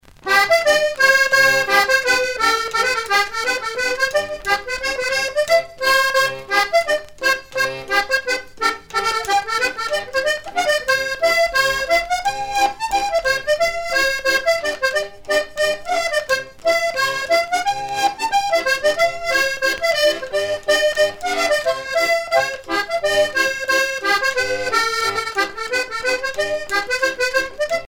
Région ou province Morvan
danse : scottich trois pas